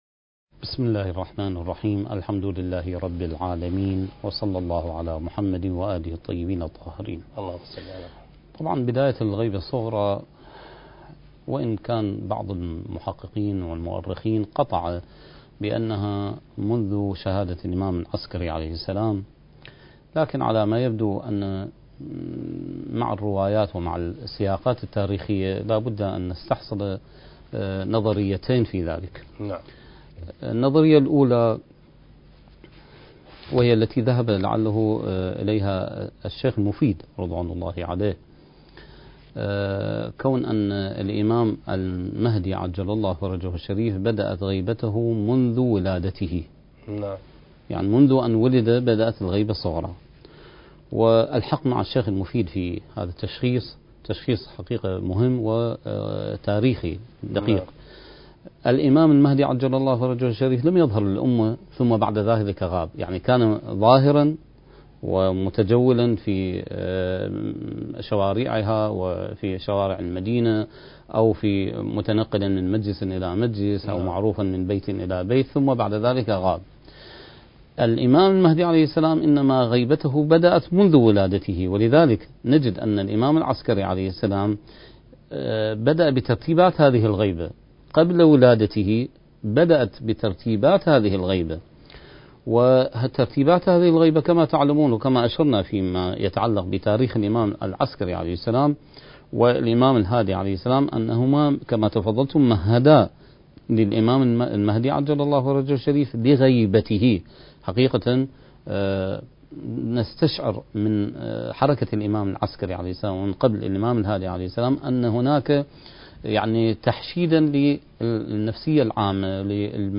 سلسلة محاضرات: بداية الغيبة الصغرى (1) برنامج المهدي وعد الله انتاج: قناة كربلاء الفضائية